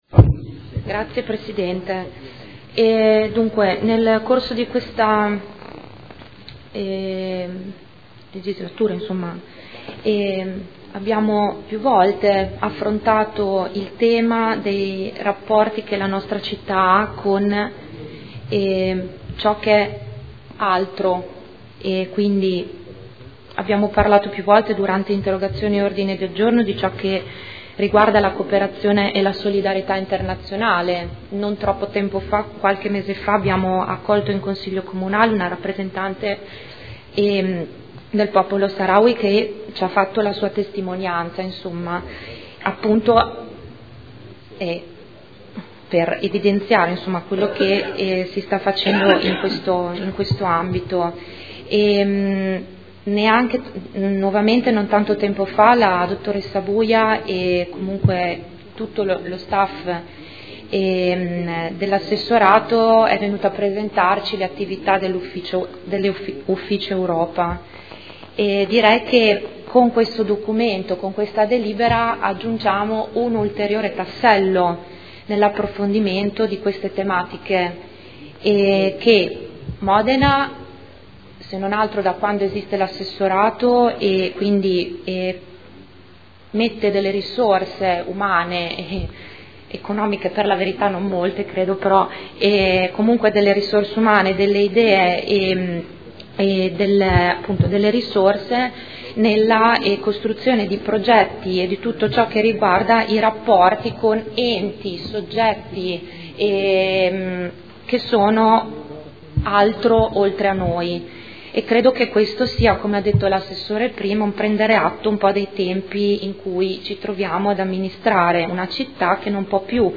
Elisa Sala — Sito Audio Consiglio Comunale
Seduta del 16 gennaio. Proposta di deliberazione: Regolamento per la costituzione, la gestione e lo sviluppo di relazioni internazionali con città, comunità e territori. Dibattito